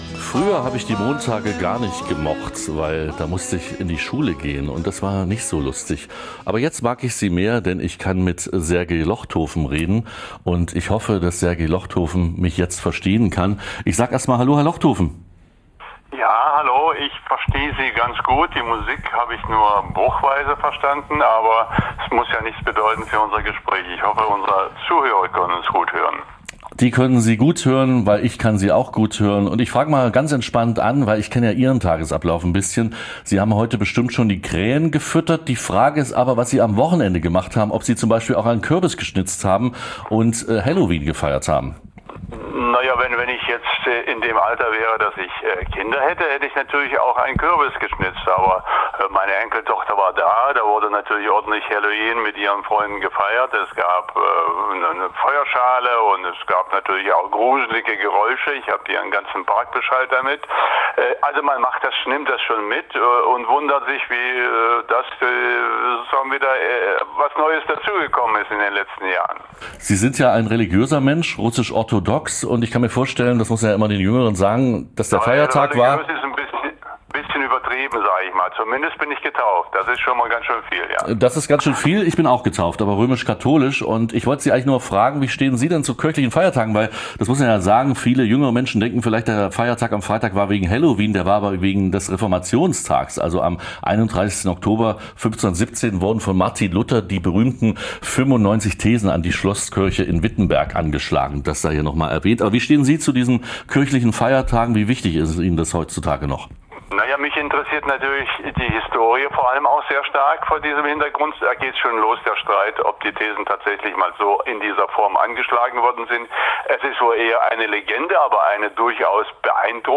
> Download Plauderei �ber Medien, Malerei und Belomorkanal